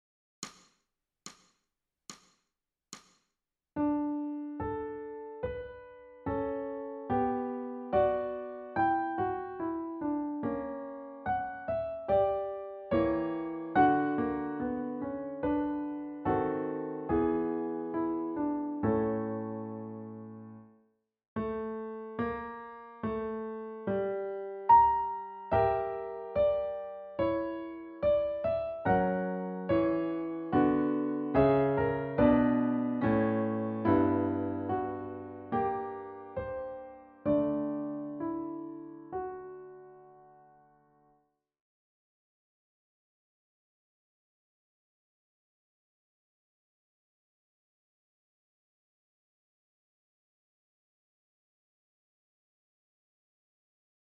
ソルフェージュ 聴音: 2-4-12